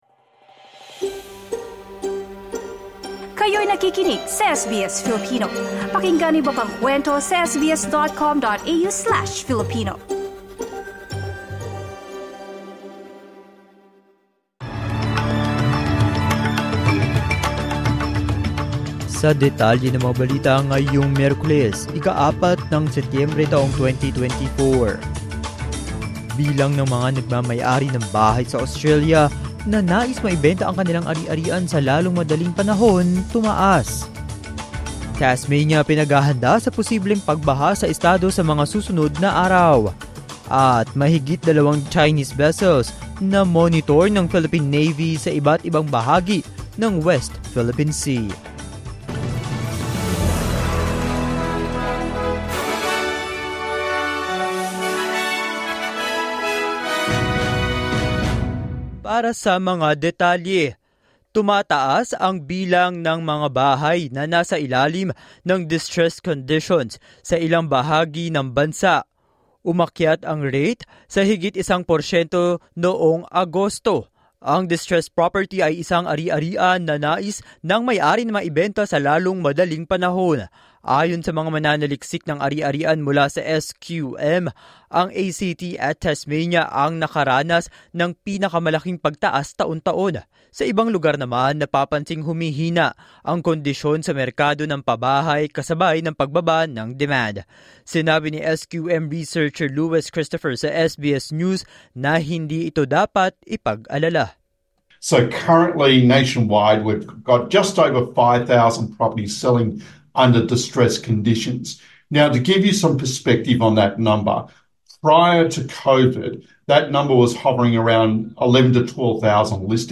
SBS News in Filipino, Wednesday 4 September 2024